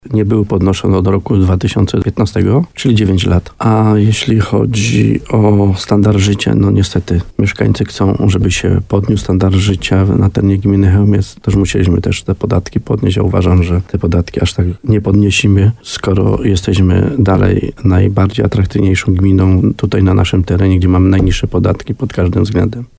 Mieszkańcy gminy Chełmiec będą płacić wyższe podatki od nieruchomości. W trakcie sesji radni zgodzili się na 30% podwyżkę. Wójt gminy Chełmiec Stanisław Kuzak komentował w programie Słowo za Słowo na antenie RDN Nowy Sącz, że zmiany stawki nie dało się uniknąć.